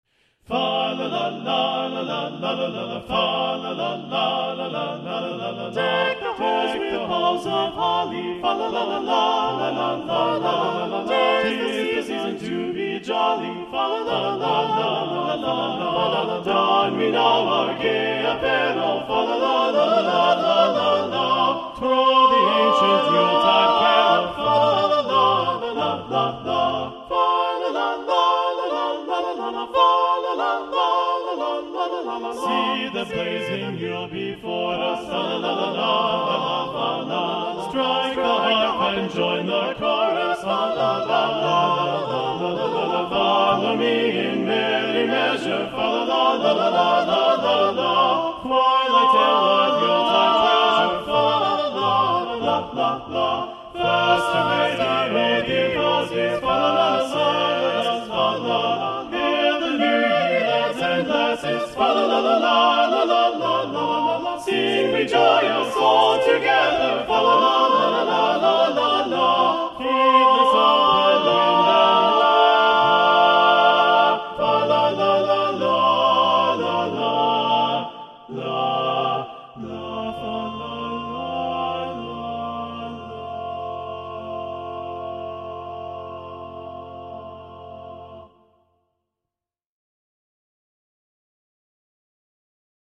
Choral Music
HOLIDAY MUSIC — A CAPPELLA